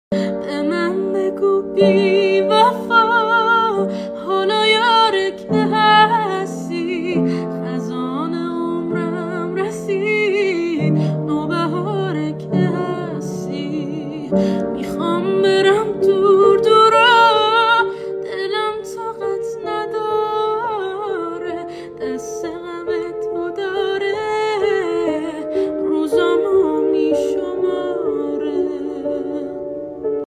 صدای زن